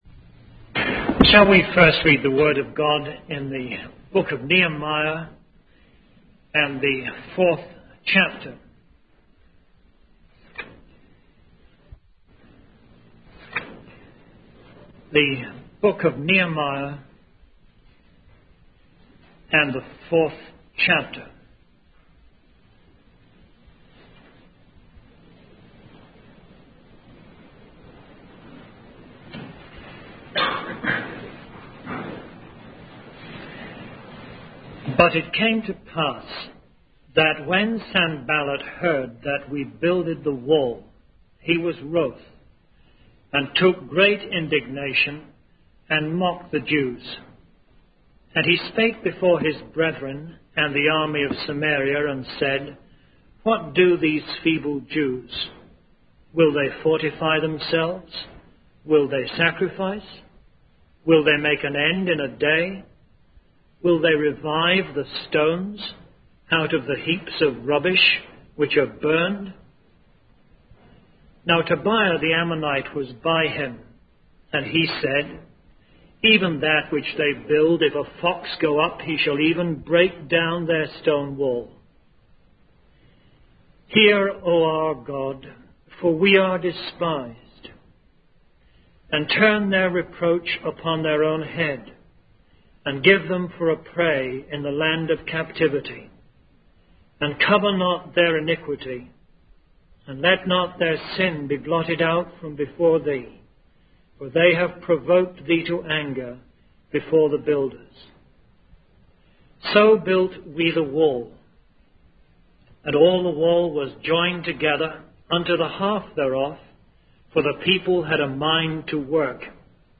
In this sermon, the speaker begins by reading from the book of Nehemiah, specifically chapter four. The speaker then discusses the importance of unity and prayer among believers in times of confusion and challenges.